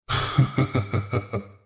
Subject description: A very electronic infantry unit voice!